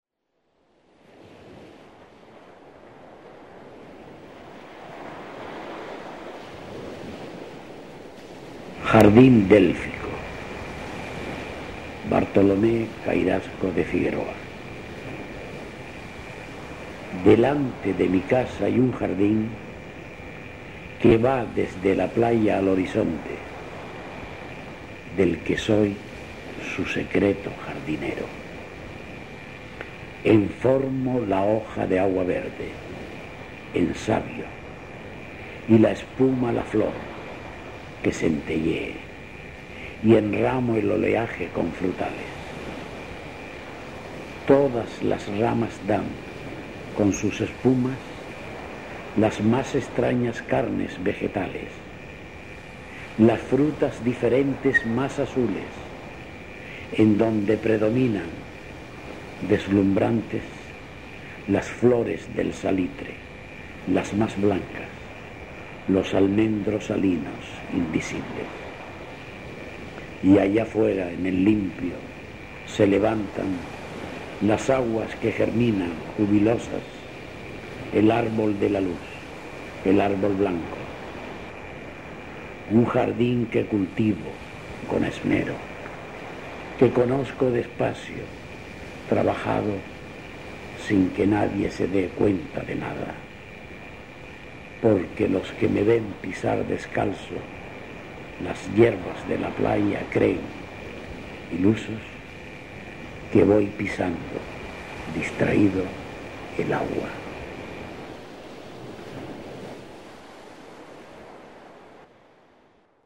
Grabación en Punta Brava
mezcla de sonido
Manuel Padorno: voz